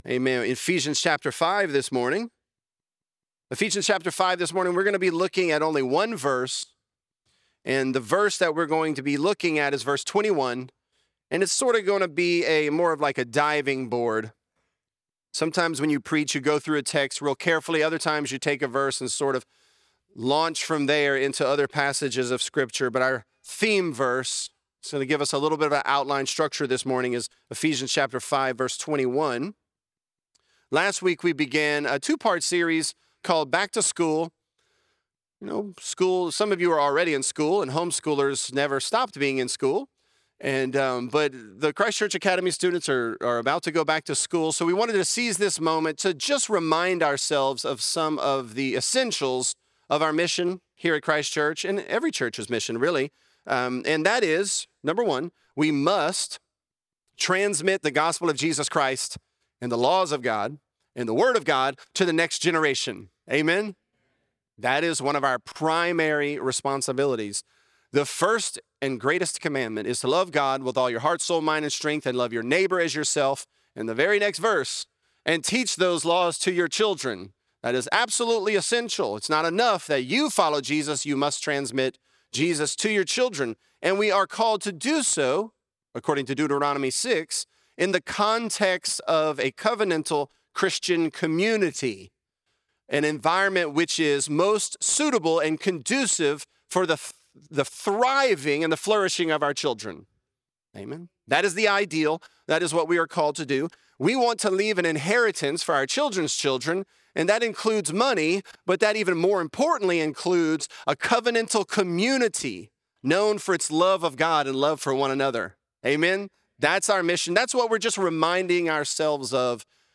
A Community That Lasts | Lafayette - Sermon (Ephesians 5)